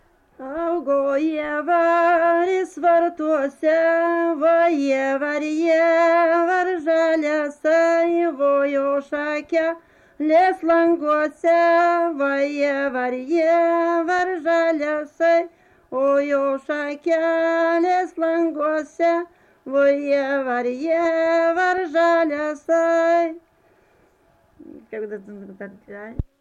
daina, kalendorinių apeigų ir darbo
Erdvinė aprėptis Druskininkai
Atlikimo pubūdis vokalinis